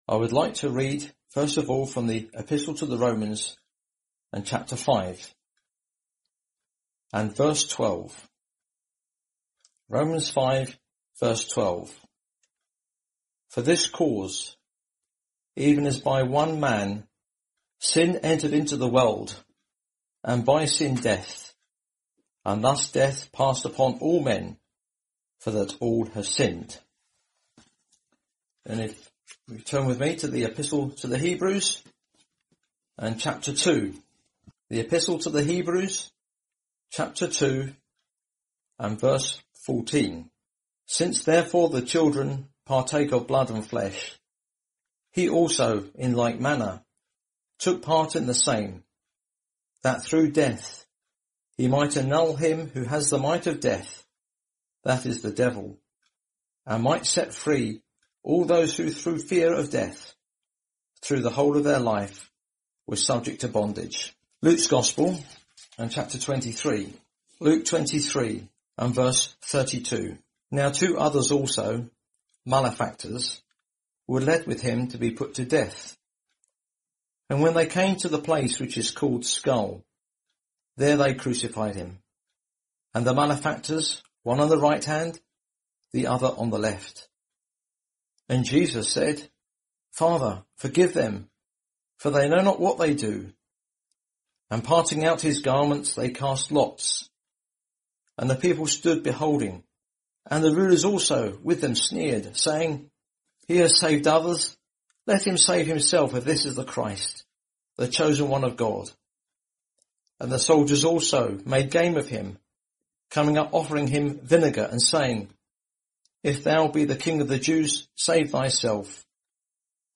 Listen to the following Gospel preaching to discover what Jesus has done to save sinners.